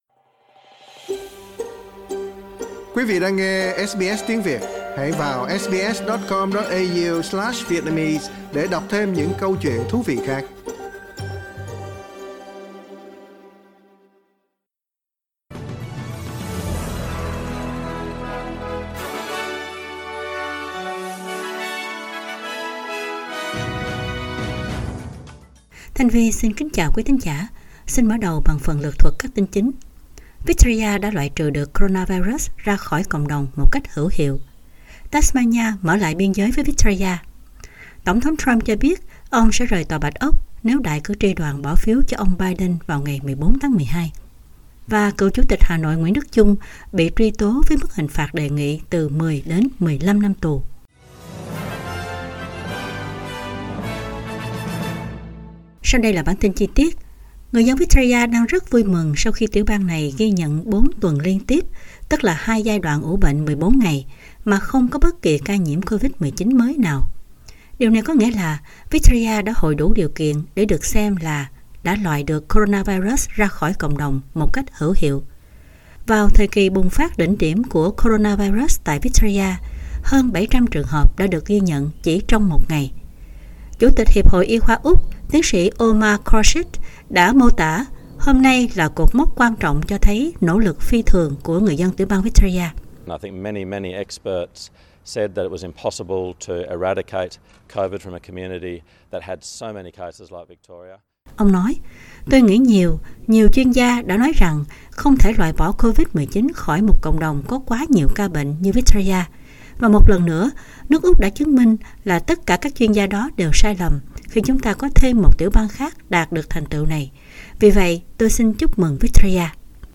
Bản tin chính trong ngày của SBS Radio.